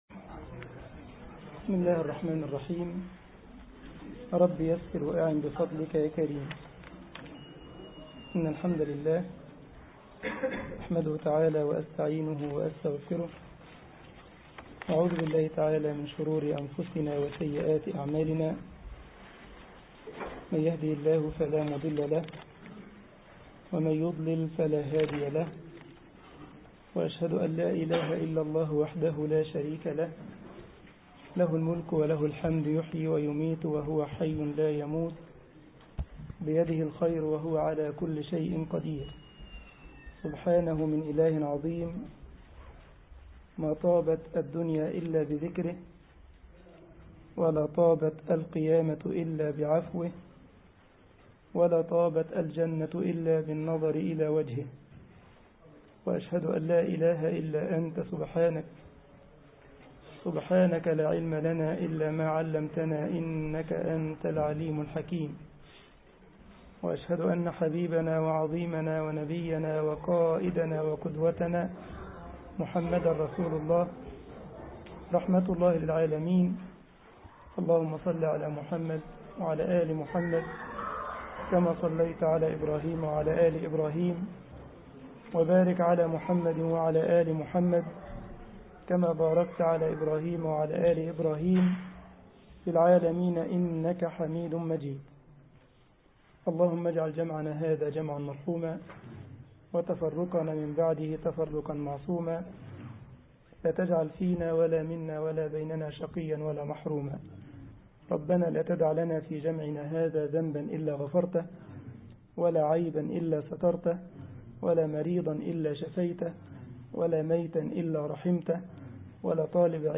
مسجد الجمعية الإسلامية بالسارلند ـ ألمانيا درس